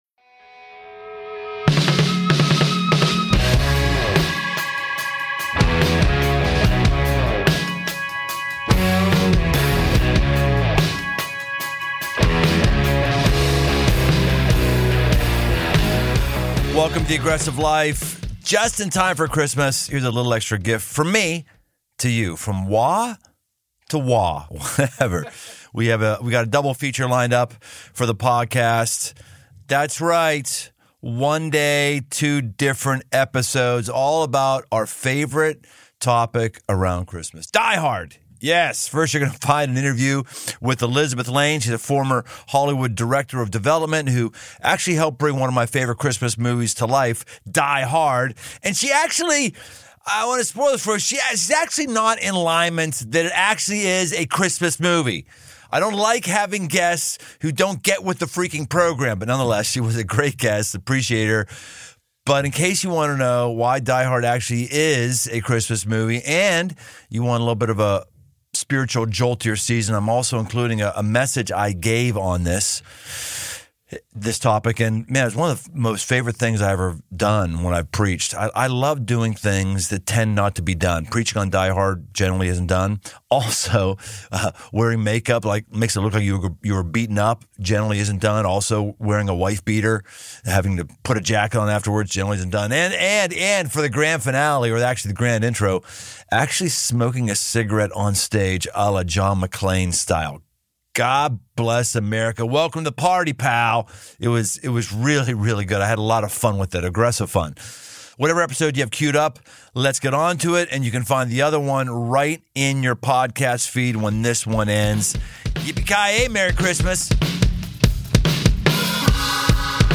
First, you're going to find an interview